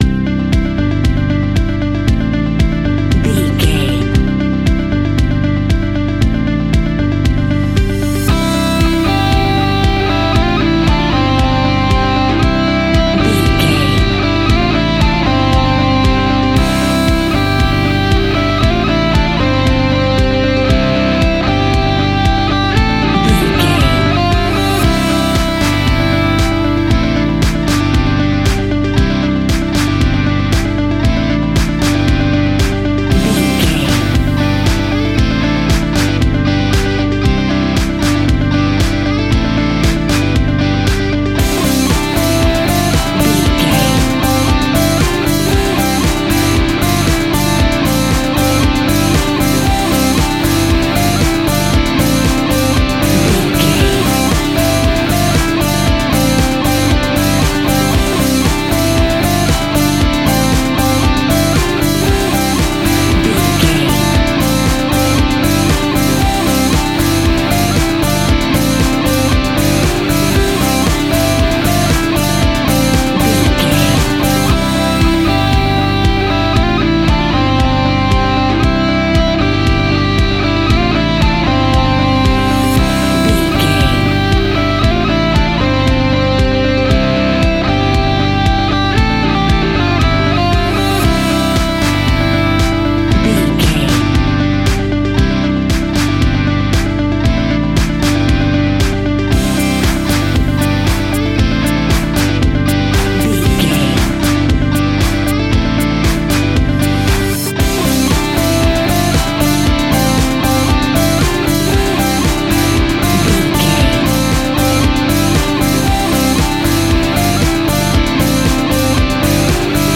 royalty free music
Ionian/Major
energetic
uplifting
instrumentals
indie pop rock music
upbeat
groovy
guitars
bass
drums
piano
organ